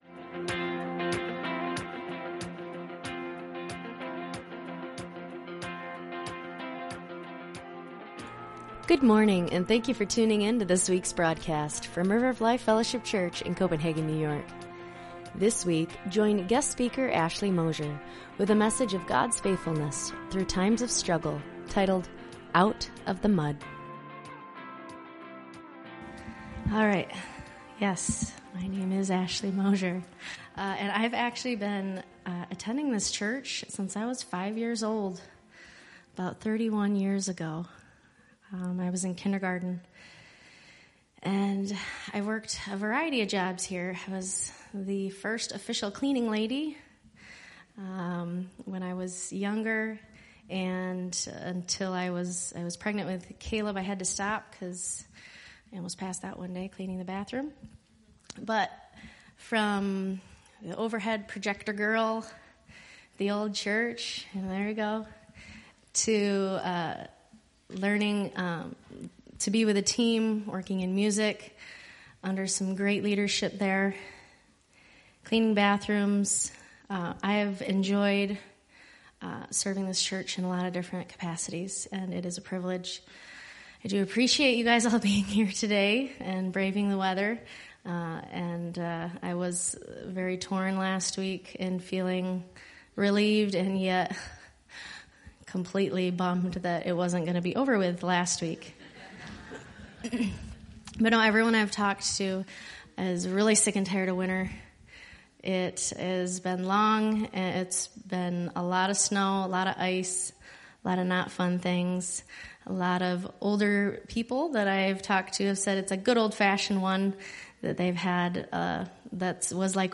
Sermons | River of Life Fellowship Church